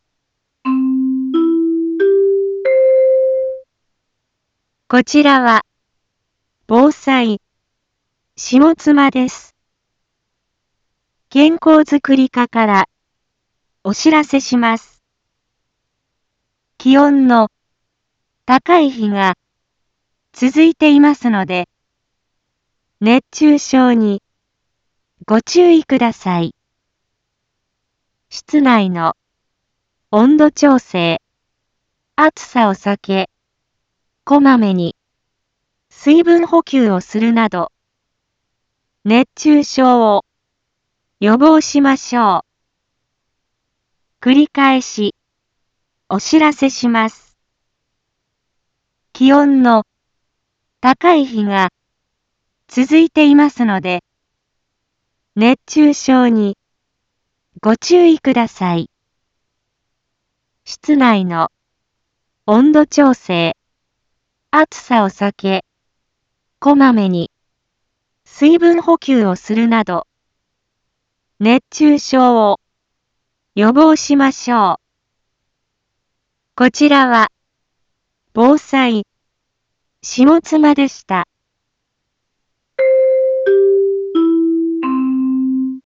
一般放送情報
Back Home 一般放送情報 音声放送 再生 一般放送情報 登録日時：2025-08-25 11:01:45 タイトル：熱中症注意のお知らせ インフォメーション：こちらは、ぼうさいしもつまです。